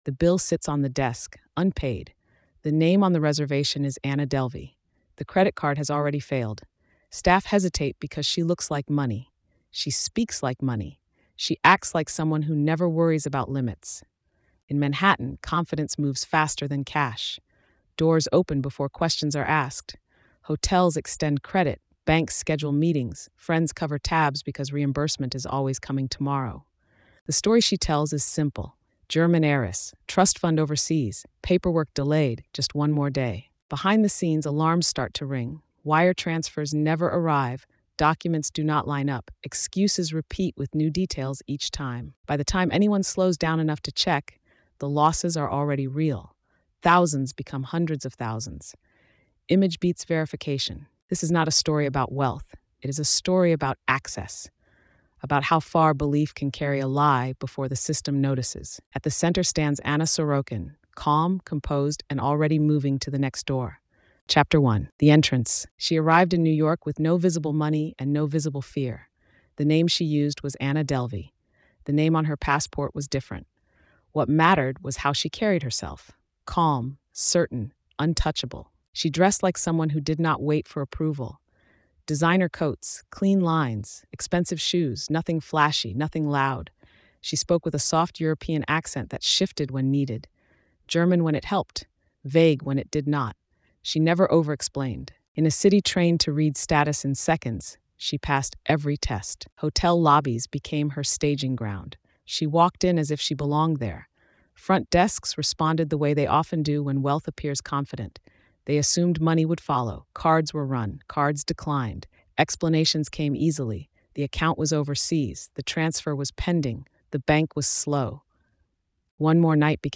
The Fake Heiress: Anna Sorokin is a gritty, forensic true-crime narrative that examines how confidence, appearance, and access allowed a young woman to move through New York’s elite spaces without money, collateral, or verification. Told in a neutral, pressure-driven tone, the story follows the rise and collapse of Anna Sorokin, also known as Anna Delvey, exposing how institutions, individuals, and systems failed not because of greed alone, but because belief repeatedly replaced proof.